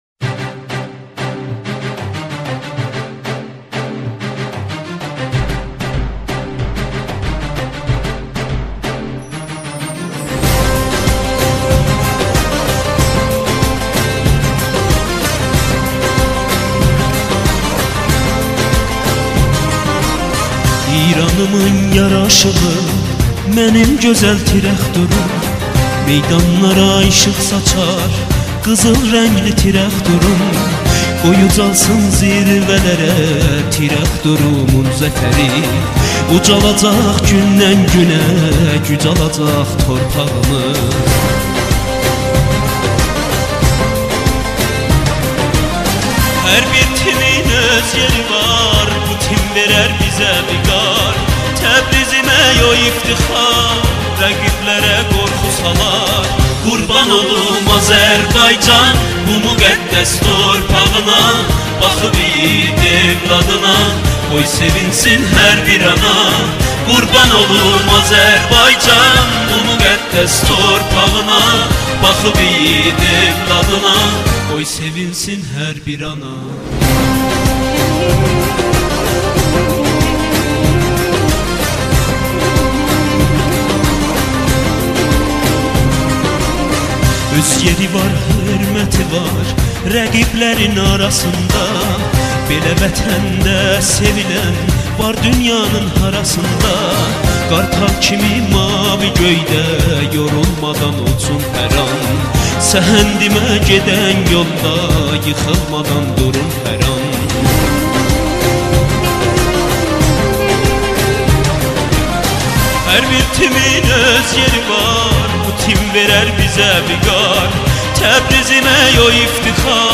آهنگ بدون کلام